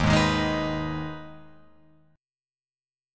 D#sus4#5 chord